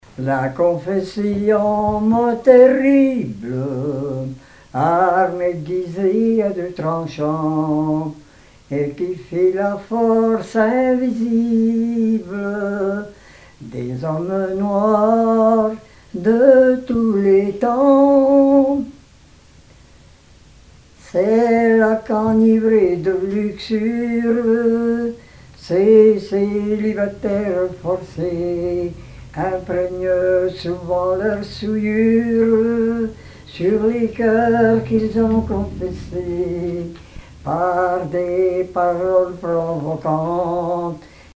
chanson anticléricale
Genre strophique
chansons et témoignages parlés